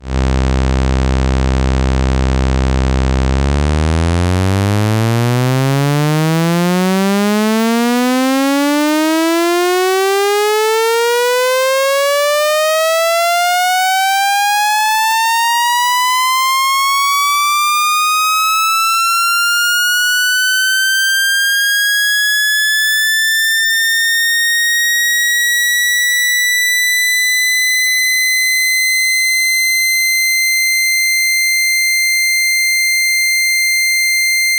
In the sweeps, the lowest note is MIDI C2, which has a fundamental of 65Hz.
viper-saw.wav